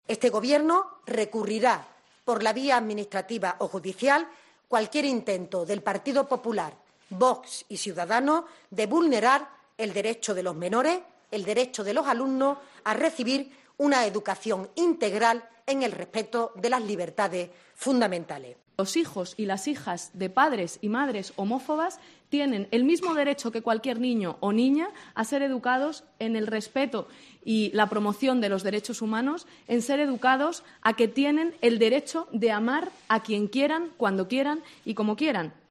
María Jesús Montero anuncia el recurso contra el 'pin parental' e Irene Montero habla de padres homófobos
Lo ha dicho la portavoz del Gobierno, María Jesús Montero, en la rueda de prensa posterior al Consejo de Ministros, donde ha destacado que el Ejecutivo "ha abordado con mucha preocupación la intención del Gobierno murciano de establecer el llamado 'pin parental'", una exigencia de Vox en la negociación de los presupuestos regionales.